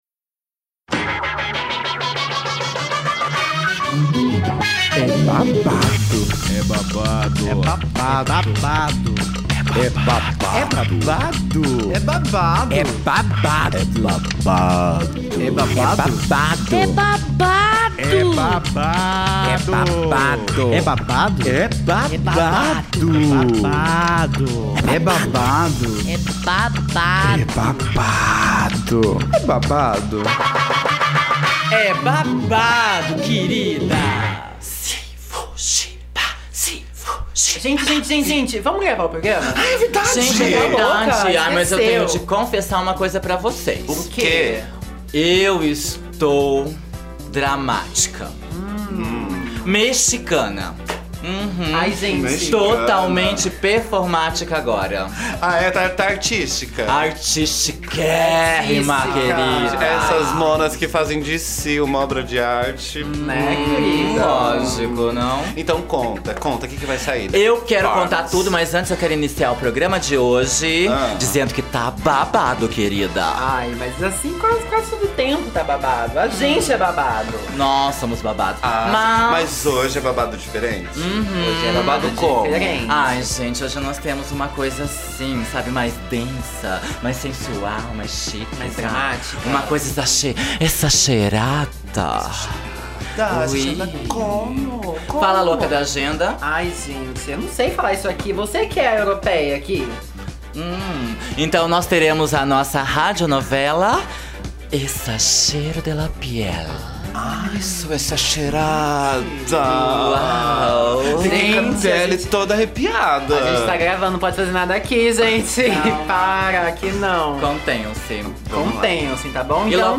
E chega com toda finesse na estreia da rádio novela “Exagero da pele”, num enredo recheado de babados que combinam drama e bom humor.